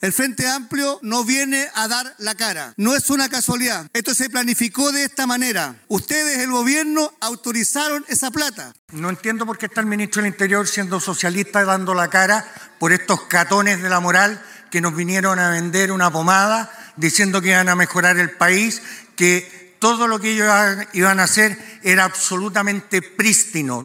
El caso ProCultura, sin dudas, ha agudizado la tensión entre oficialismo y oposición, lo que quedó demostrado la tarde de este lunes, cuando se llevó a cabo una tensa sesión especial en la Sala de la Cámara de Diputados y Diputadas, que terminó con la acusación de una supuesta agresión entre parlamentarios y múltiples dardos cruzados.
Dardos cruzados en sesión especial por ProCultura